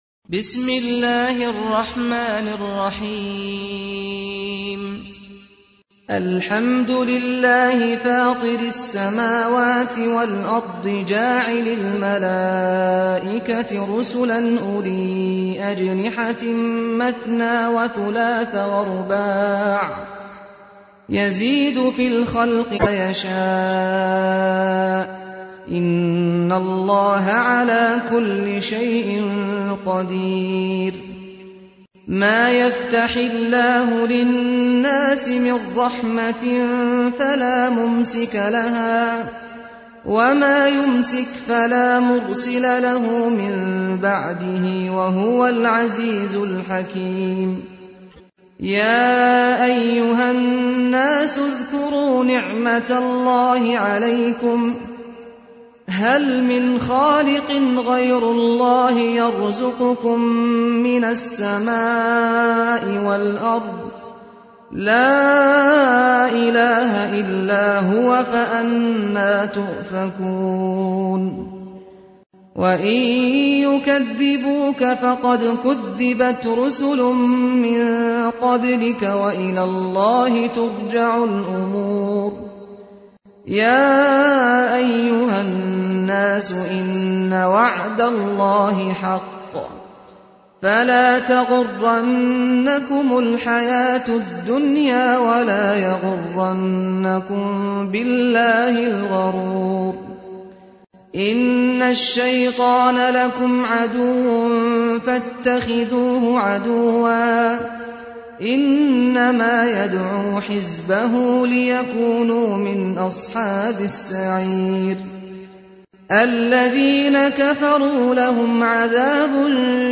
تحميل : 35. سورة فاطر / القارئ سعد الغامدي / القرآن الكريم / موقع يا حسين